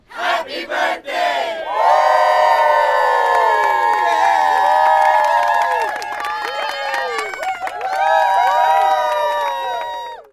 Happy Birthday Crowd
Applause Birthday Clapping Crowd Group Happy Happy-Birthday Outdoor sound effect free sound royalty free Sound Effects